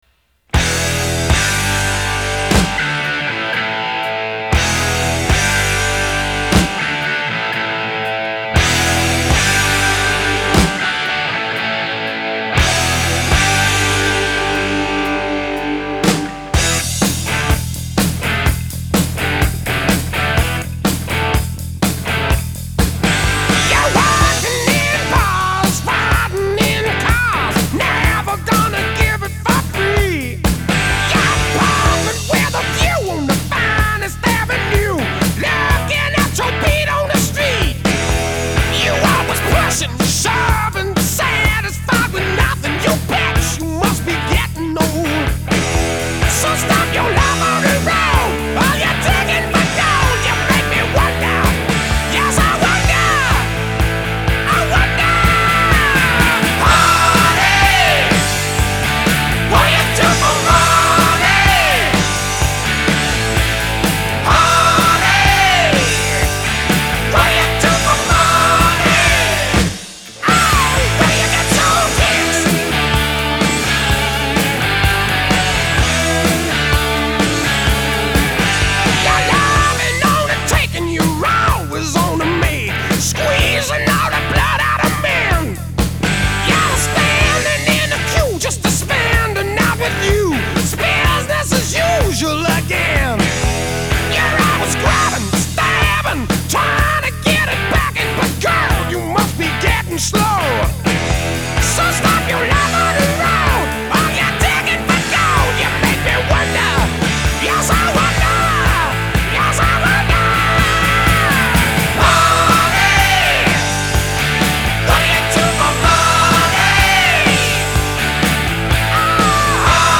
Жанр: Hard Rock, Heavy Metal